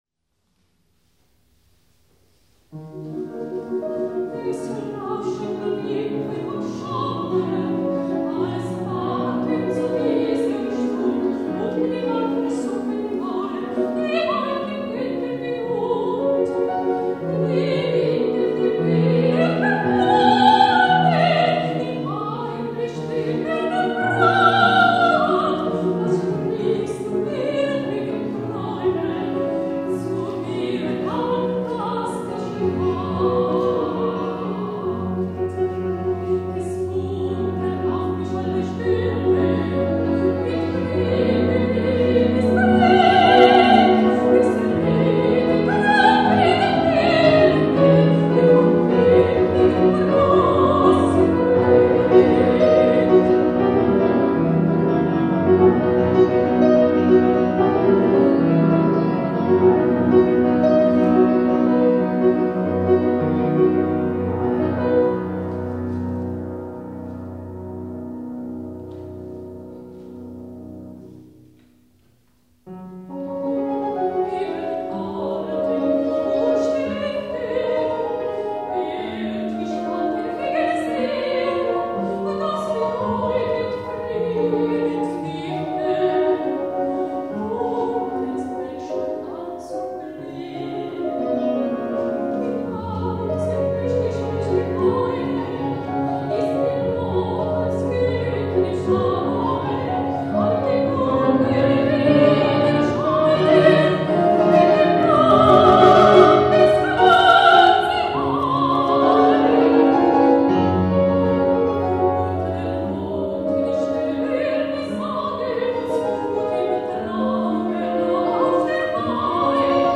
esecuzione dal vivo
Schumann live.mp3